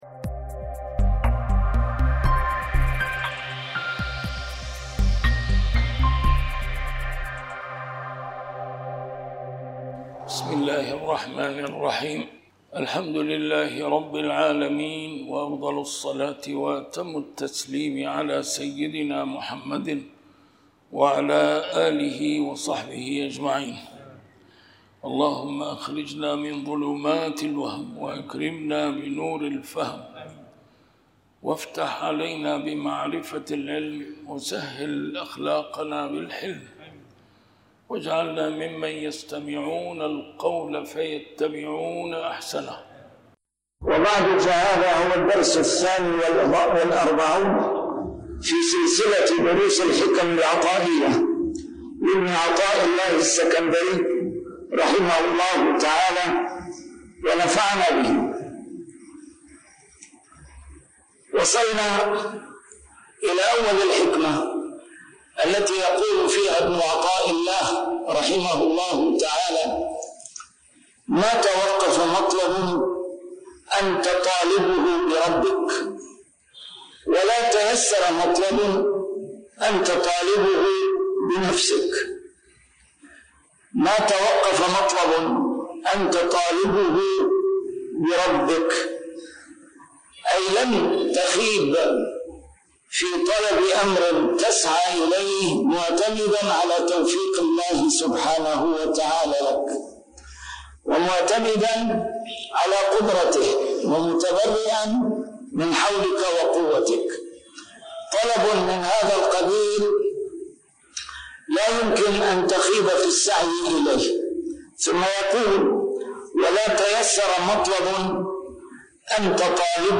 A MARTYR SCHOLAR: IMAM MUHAMMAD SAEED RAMADAN AL-BOUTI - الدروس العلمية - شرح الحكم العطائية - الدرس رقم 42 شرح الحكمة 25